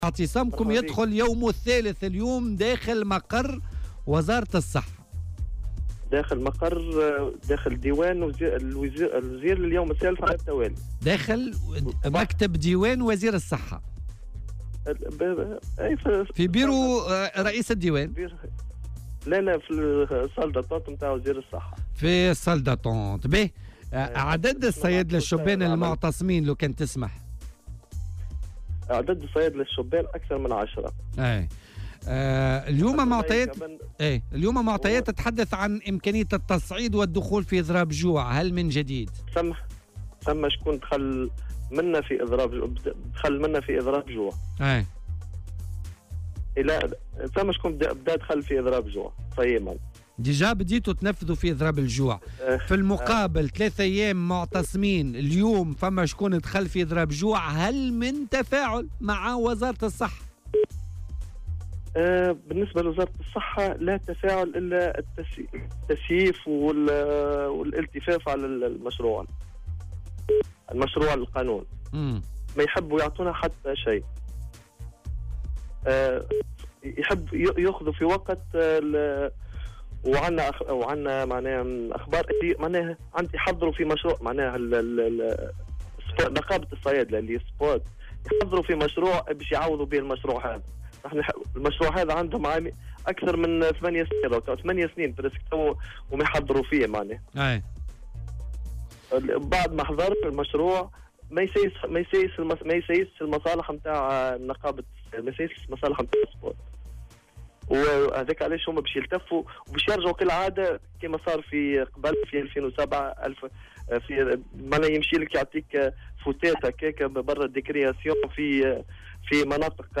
في مداخلة له اليوم في برنامج "بوليتيكا"